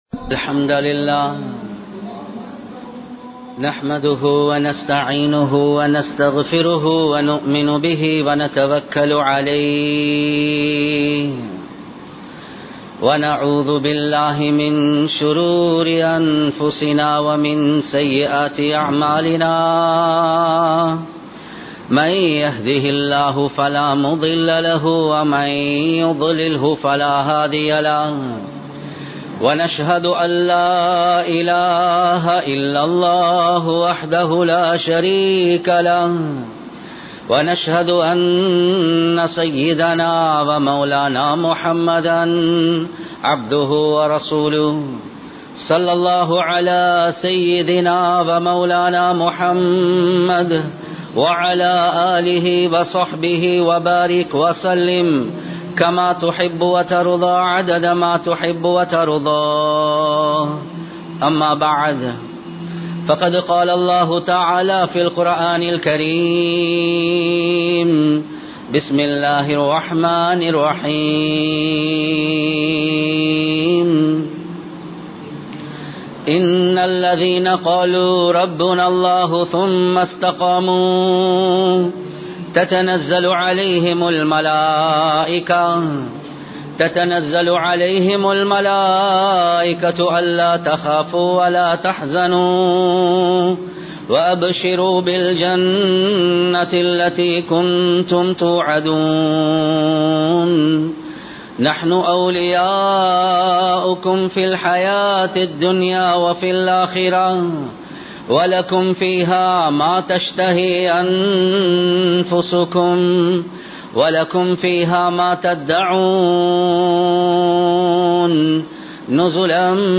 முஃமின் என்றால் யார்? | Audio Bayans | All Ceylon Muslim Youth Community | Addalaichenai
Colombo 03, Kollupitty Jumua Masjith